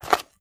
STEPS Dirt, Walk 21.wav